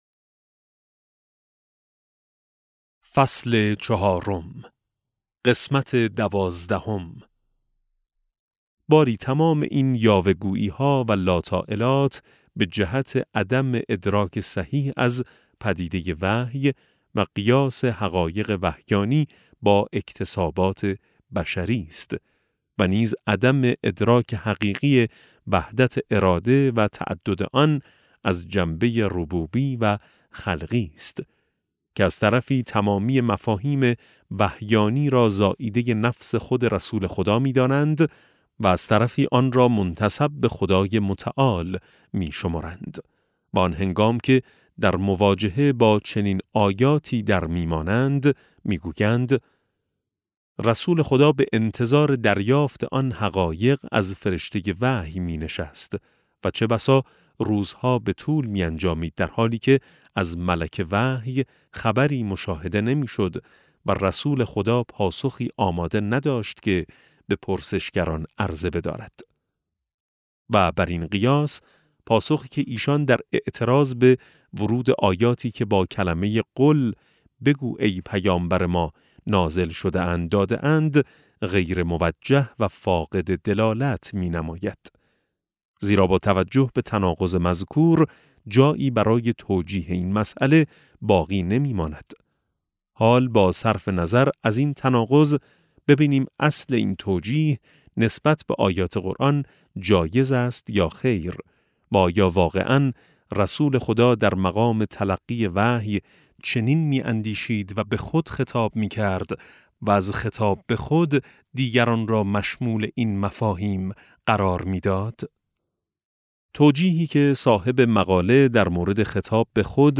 افق وحی - فصل چهارم (469 ـ 486) کتاب صوتی افق وحی - جلسه 27 پدیدآور آیت‌اللَه سید محمدمحسن حسینی طهرانی توضیحات افق وحی - فصل چهارم: بررسی مطالب مطرح شده از طرفین - صفحه (469 ـ 486) متن این صوت دانلود این صوت